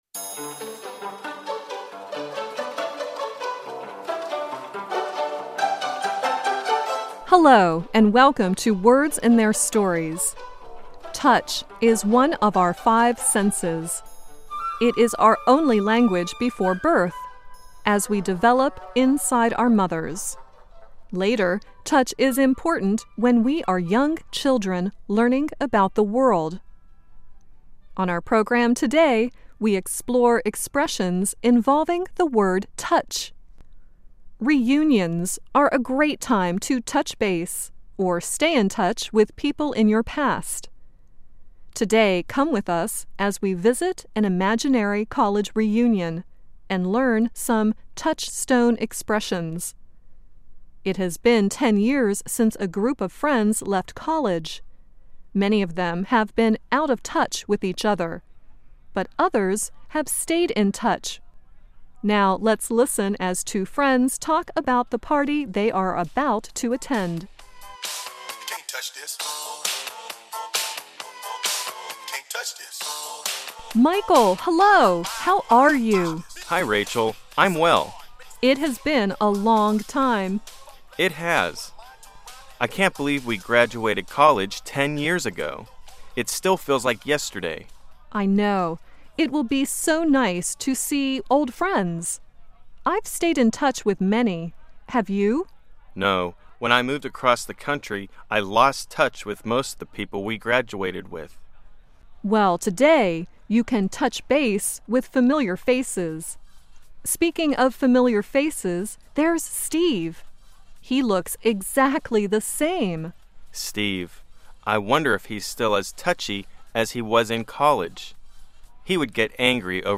And it is very useful in everyday American English. Master this word with a lively dialogue.